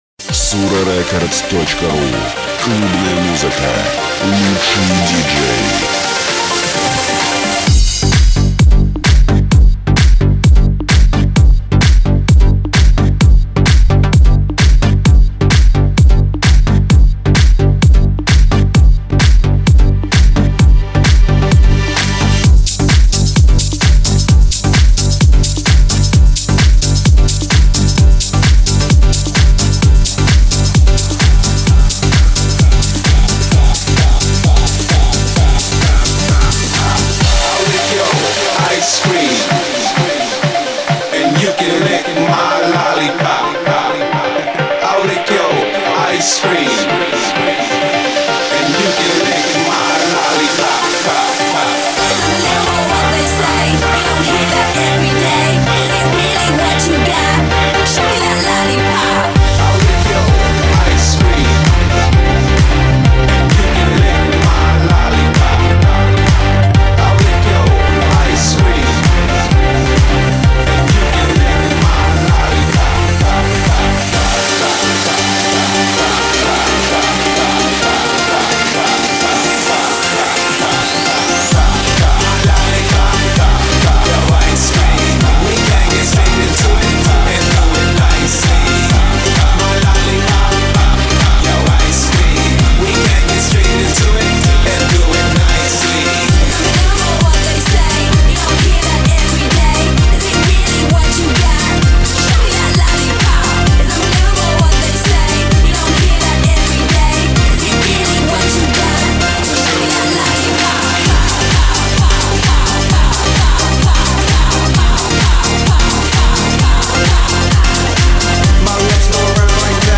mix
Волшебный микс !
реально качает!